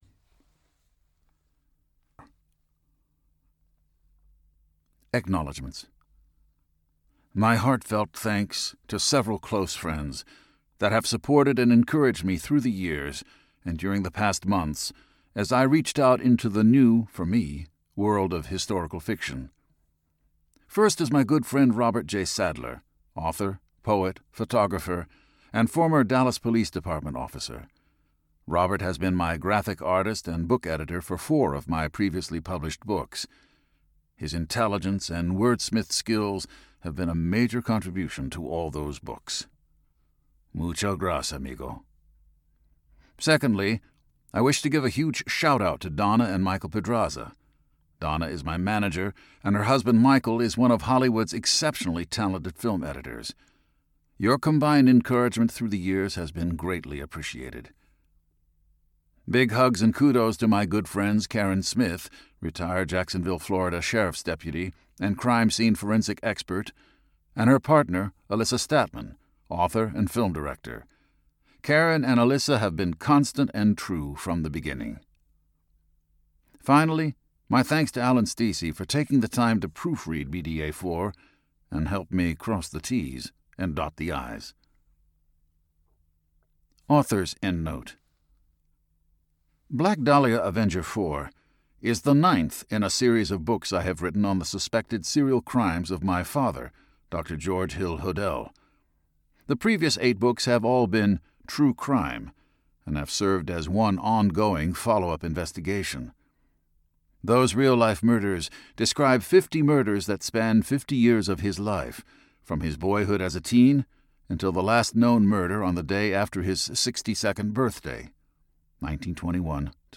With the latest publication of Black Dahlia Avenger IV this month, in print, ebook, and audiobook, I believe I can personally acknowledge to myself-“CASE CLOSED.”